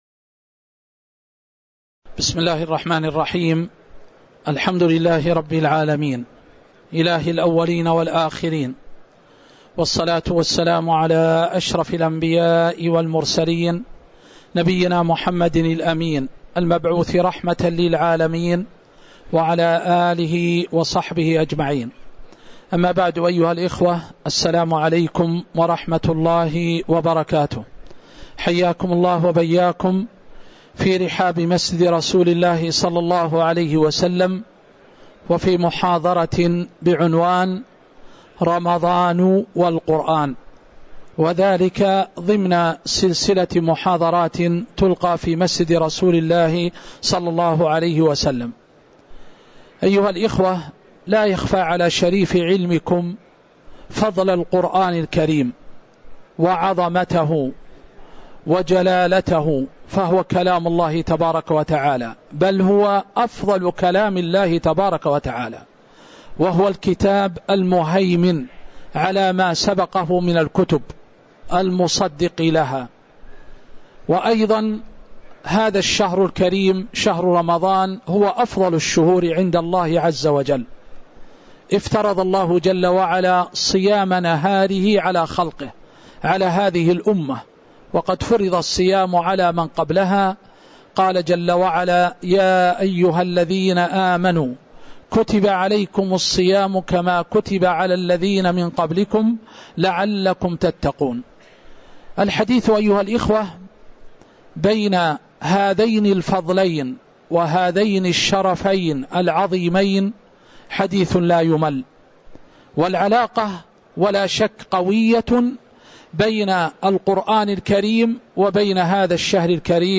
تاريخ النشر ٨ رمضان ١٤٤٦ هـ المكان: المسجد النبوي الشيخ